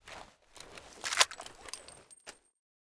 290378b961 Divergent / mods / Tommy Gun Drop / gamedata / sounds / weapons / thompson / 1921_new_unjam.ogg 85 KiB (Stored with Git LFS) Raw History